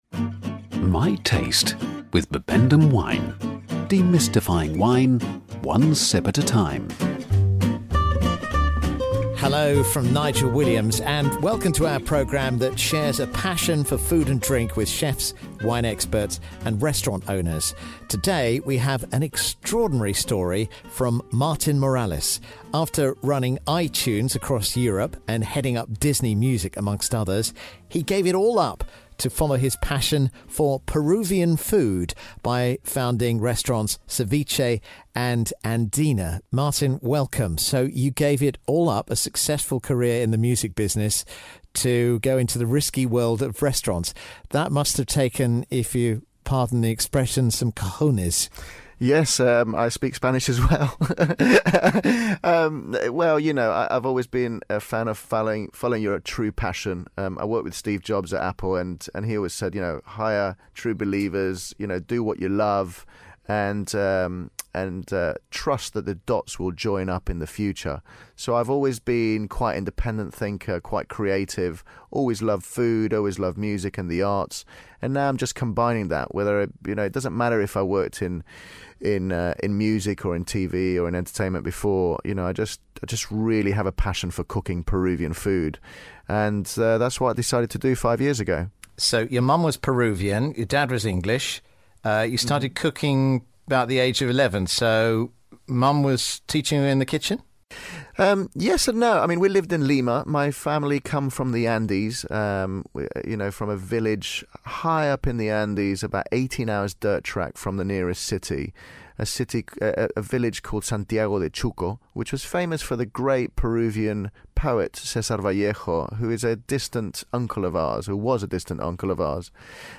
a series of programmes interviewing renowned chefs and restaurateurs.